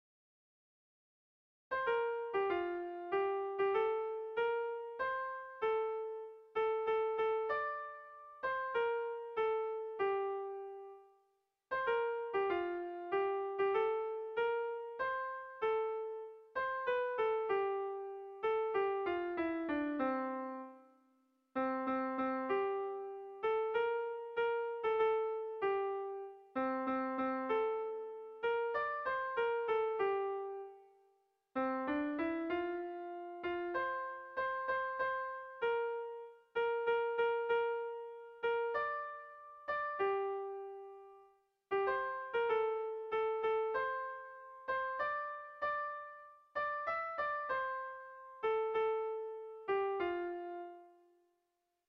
Erlijiozkoa
Zortziko handia (hg) / Lau puntuko handia (ip)
A1A2BD